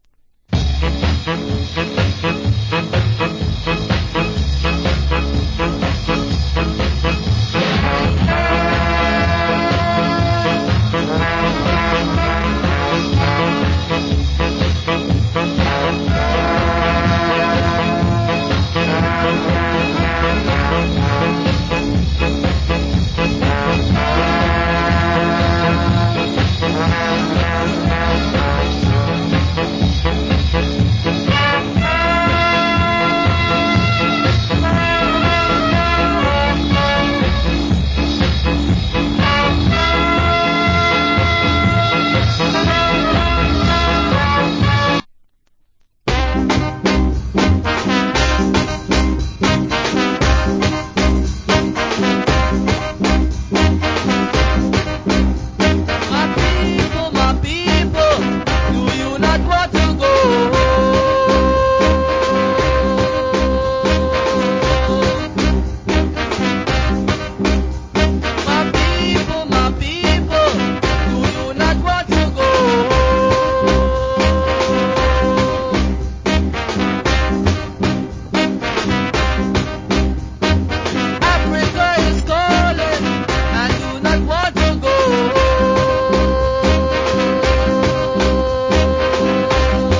Killer Ska Inst.